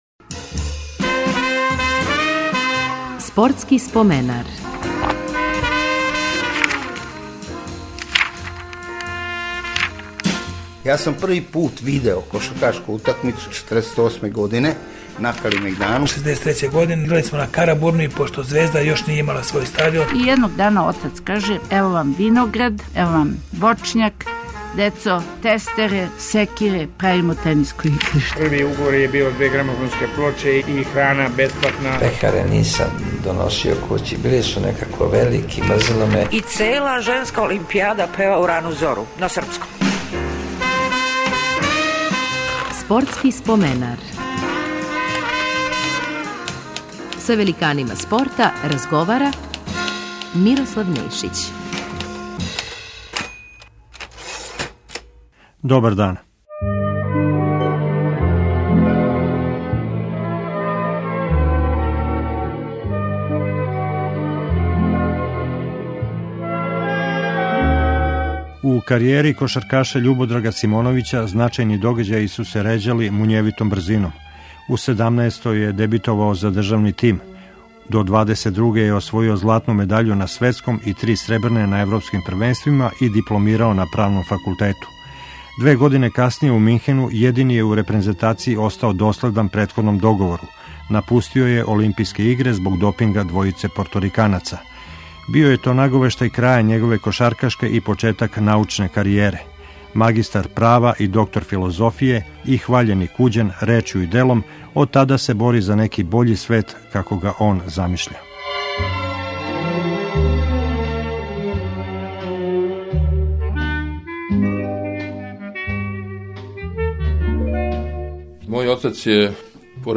Репризираћемо емисију у којој је гост био кошаркаш Љубодраг Дуци Симоновић.